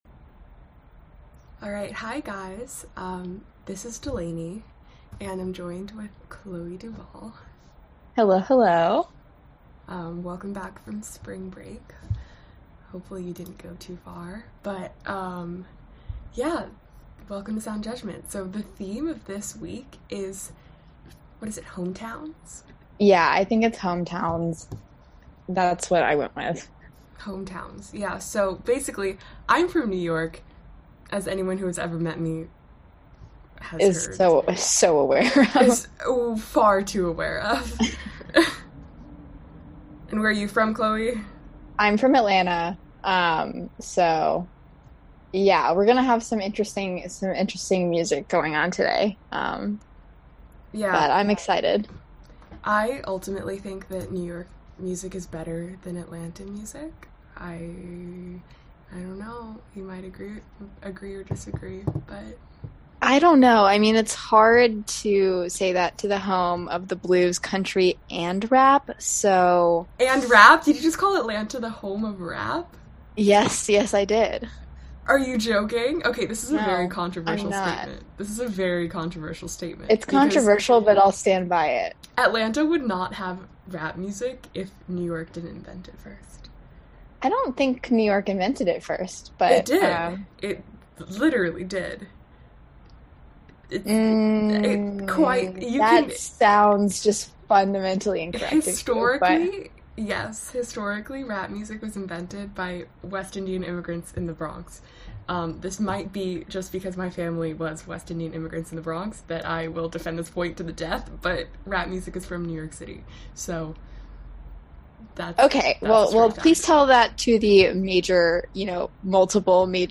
Every week will feature a different theme, spanning across genres and decades with short introductions from the hosts.